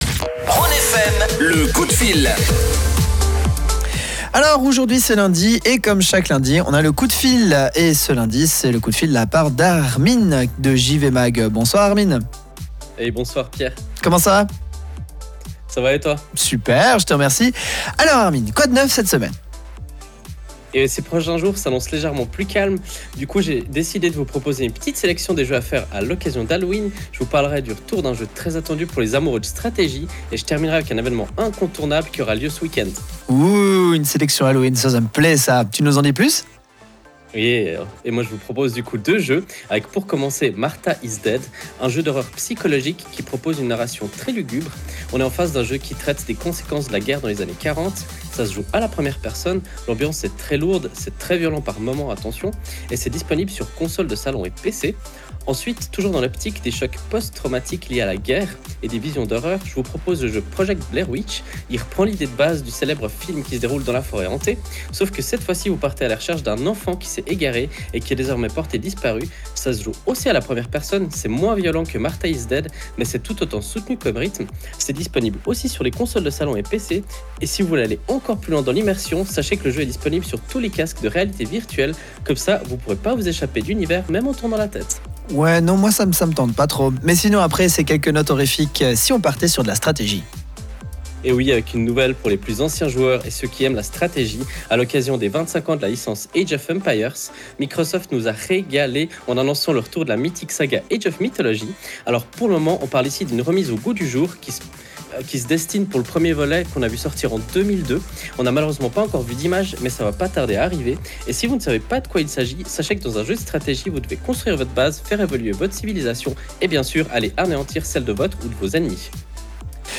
En cette soirée d’Halloween, et à l’occasion de notre chronique hebdomadaire sur la radio Rhône FM, on vous propose une petite sélection de jeux d’horreur. On revient aussi sur l’annonce inattendue de l’incroyable Age of Mythology et on termine avec l’événement FestiGames pour lequel nous seront exposants.